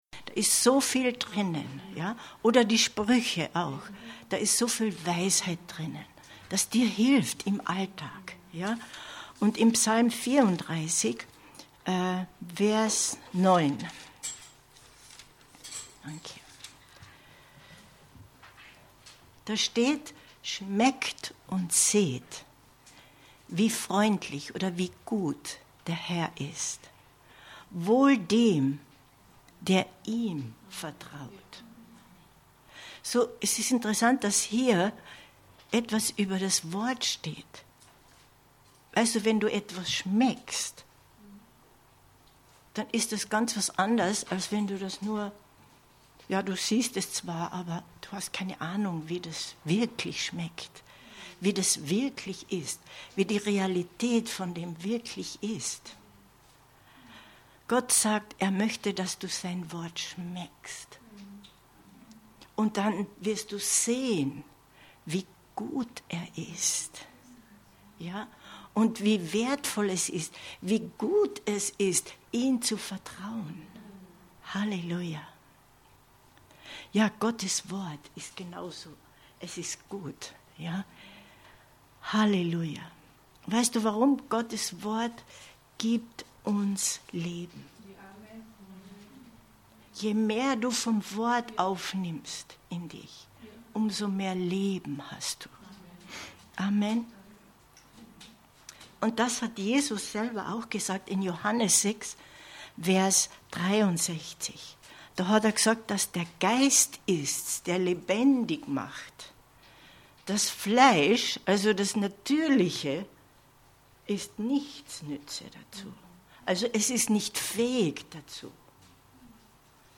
Info Info Die mächtige Kraft seiner Worte 05.02.2023 Predigt herunterladen